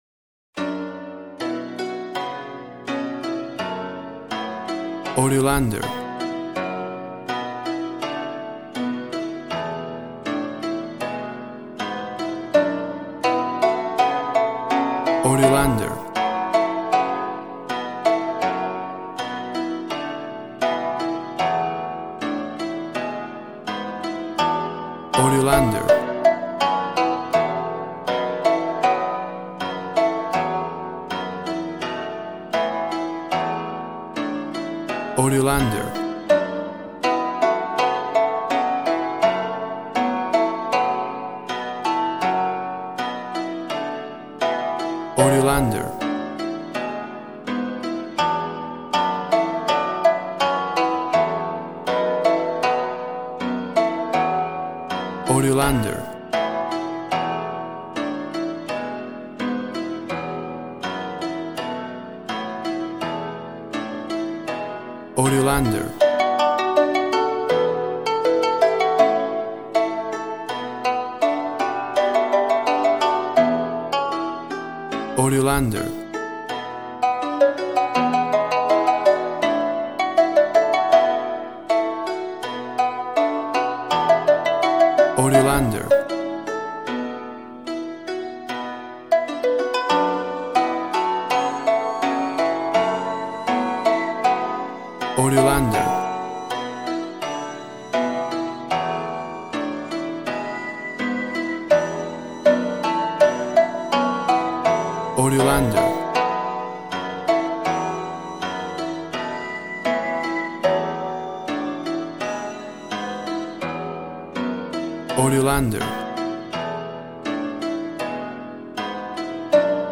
WAV Sample Rate 16-Bit Stereo, 44.1 kHz
Tempo (BPM) 80